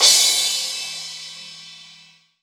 4SA CYMB.WAV